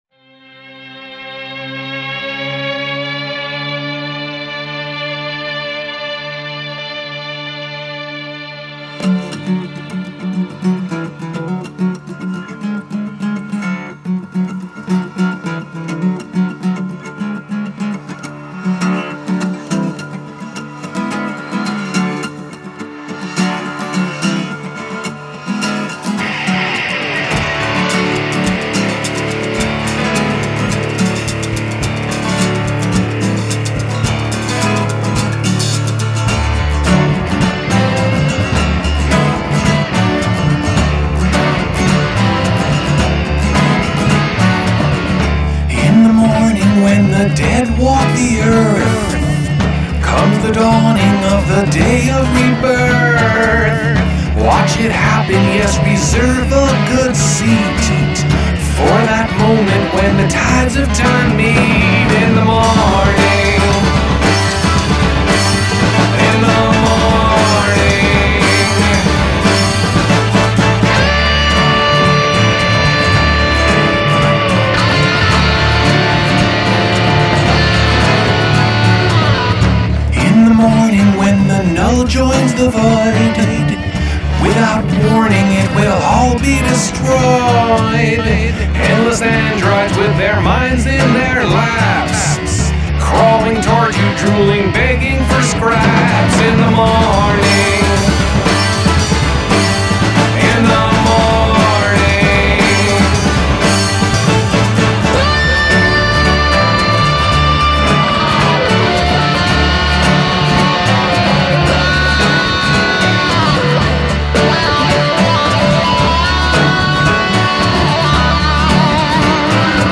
drums, percussion
piano
bass
lead guitars